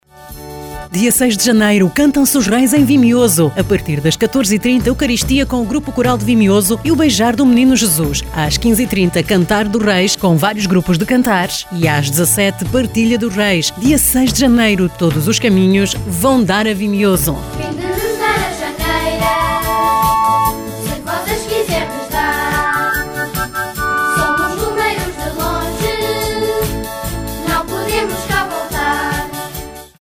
CMV_-_CANTAR_DOS_REIS_2019.mp3